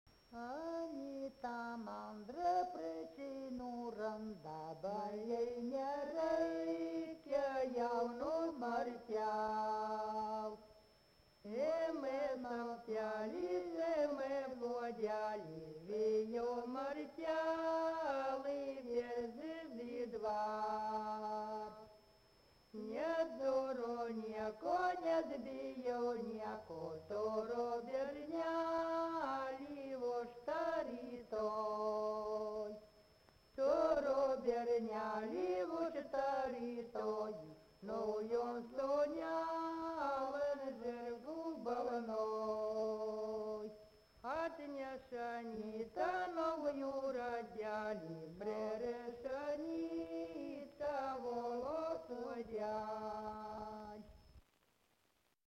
Subject daina
Erdvinė aprėptis Viečiūnai
Atlikimo pubūdis vokalinis